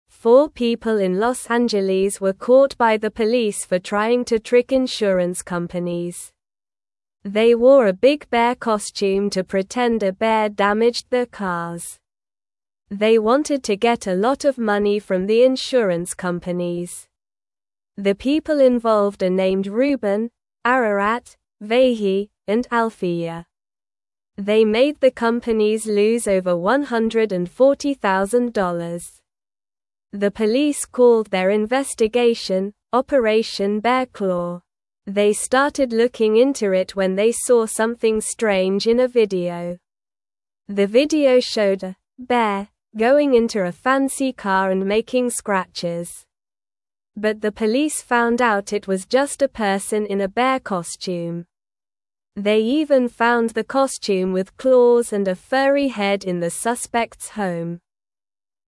Slow
English-Newsroom-Beginner-SLOW-Reading-People-Pretend-Bear-Damaged-Cars-for-Money.mp3